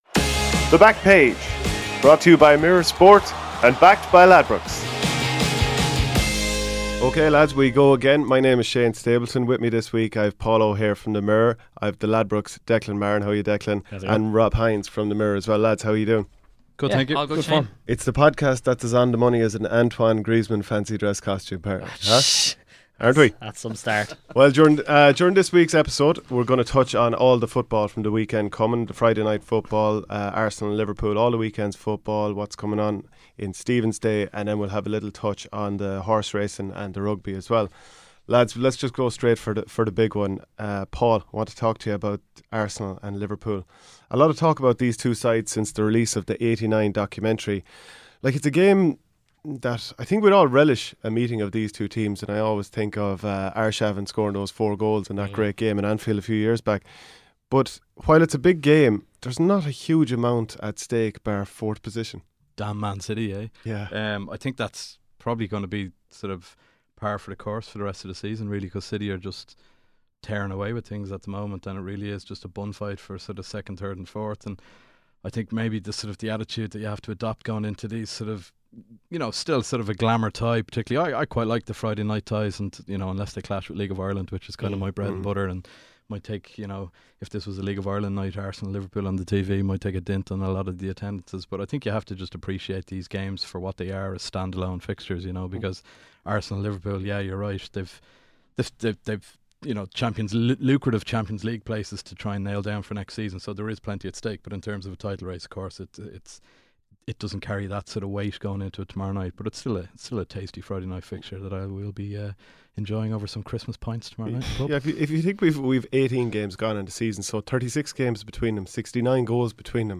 Banter, betting tips and predictions about the week's sporting action with our expert panel.